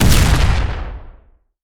etfx_explosion_nuke.wav